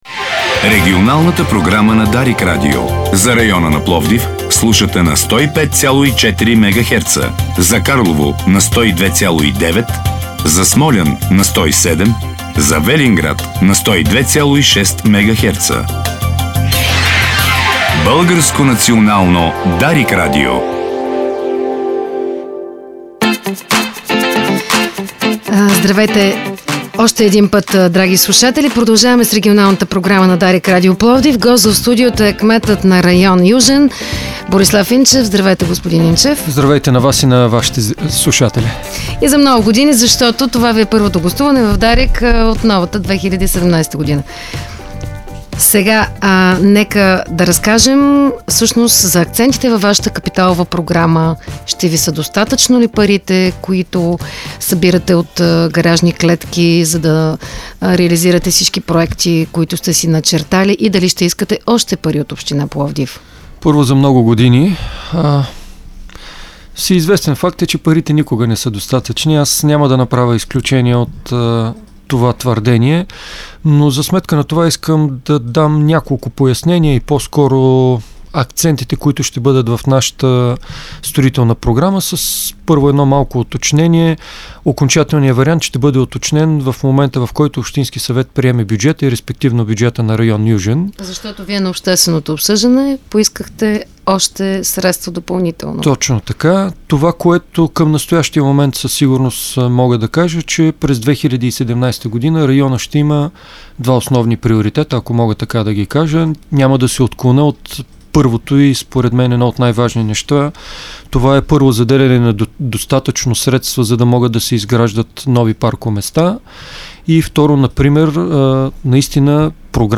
Нови тротоари ще има в пловдивския район „Южен“. Това съобщи в студиото на Дарик радио районният кмет Борислав Инчев. 22 000 лв. от бюджета са заделени именно за това, като се очаква изграждането им да започне след няколко месеца.